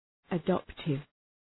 Προφορά
{ə’dɒptıv}